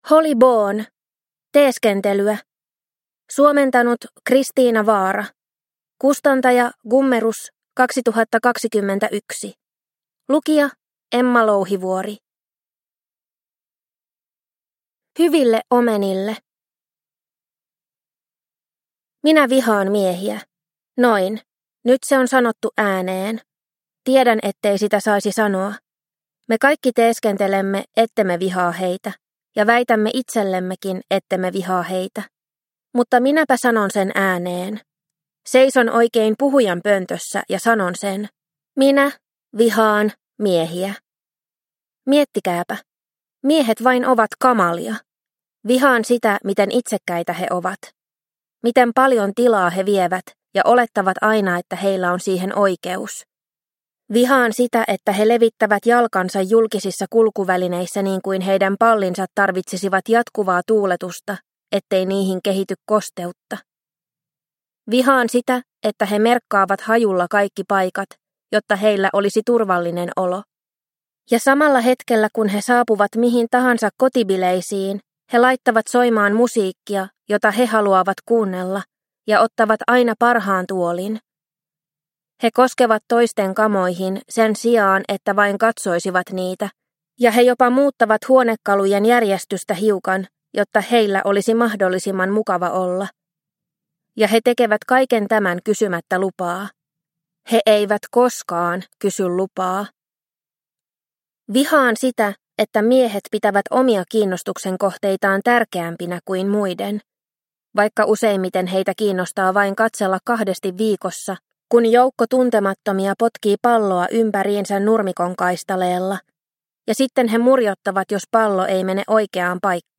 Teeskentelyä – Ljudbok – Laddas ner